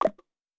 Buzz Error (8).wav